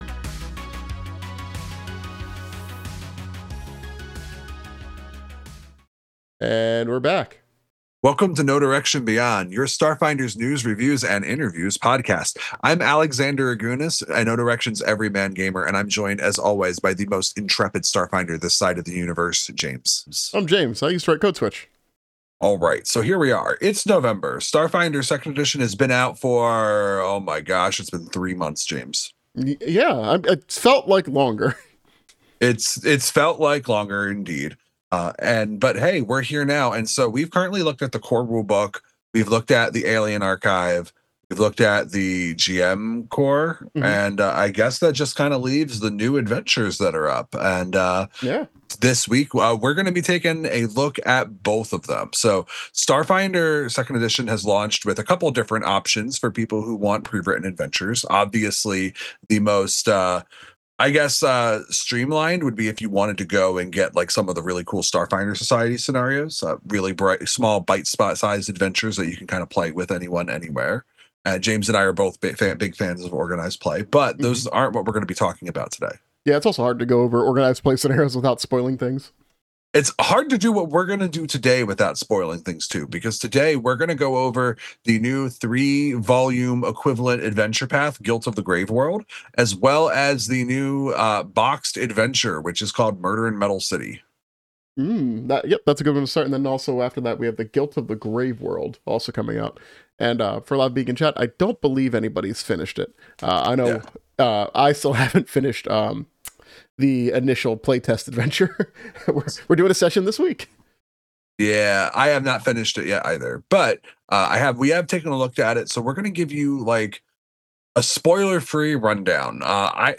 Recorded live on Twitch.